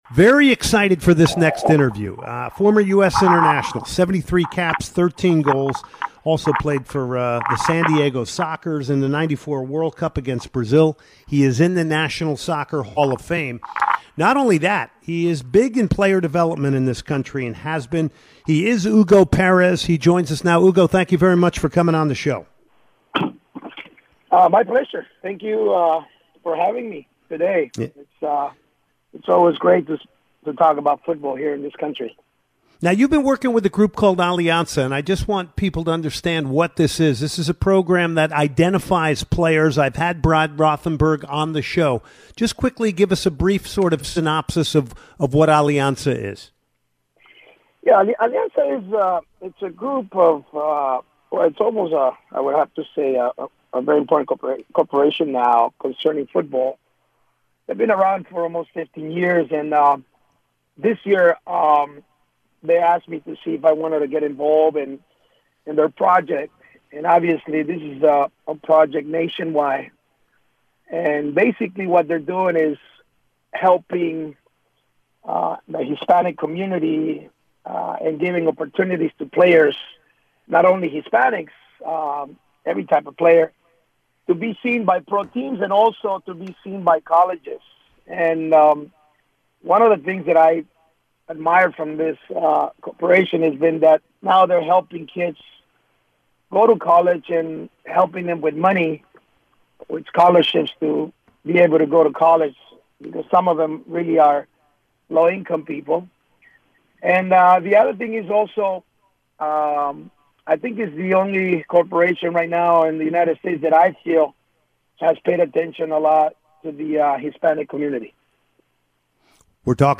09/09/2019 Soccer Matters Full Interview w/ Former USMNT Player and Youth Coach Hugo Perez